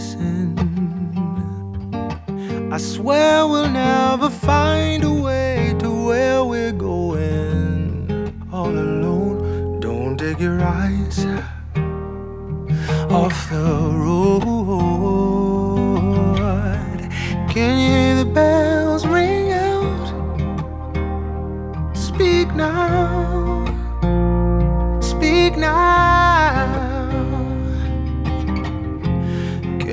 • Soundtrack